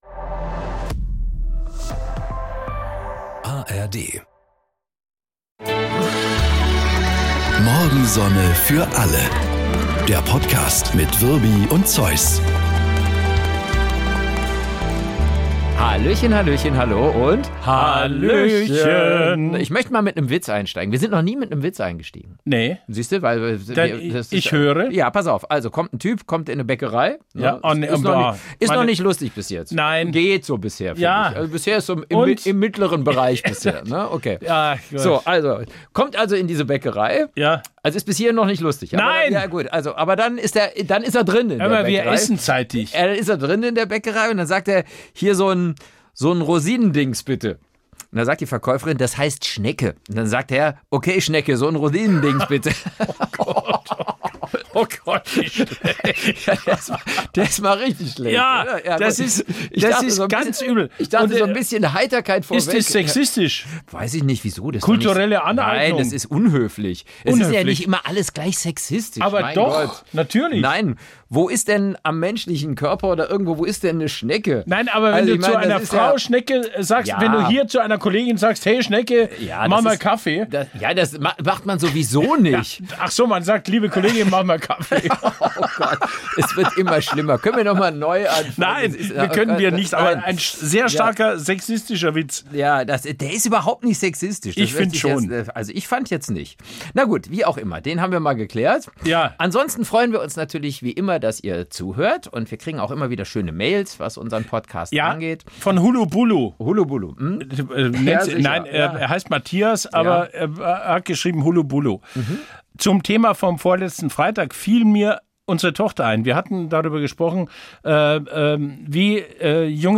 Und sind damit noch munterer für diesen Podcast: Ein großer, dicker Bayer und ein kleiner, schmaler Rheinländer machen große, dicke Gags und kleine, schmale Gemeinheiten.